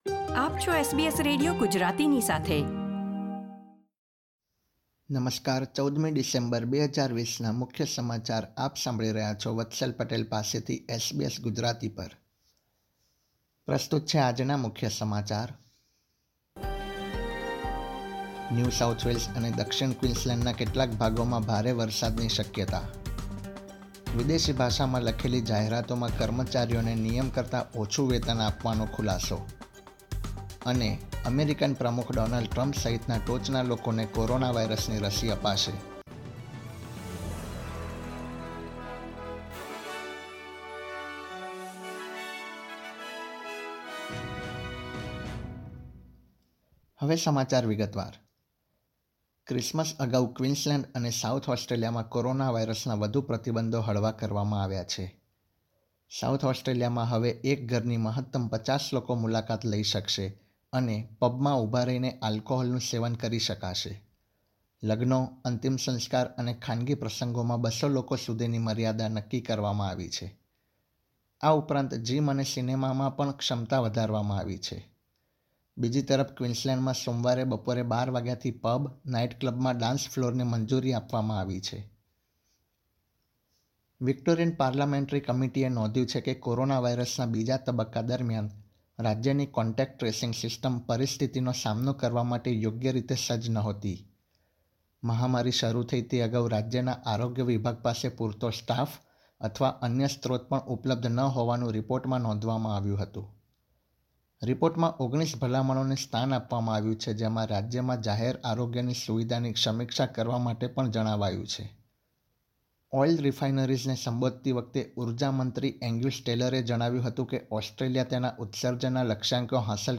SBS Gujarati News Bulletin 14 December 2020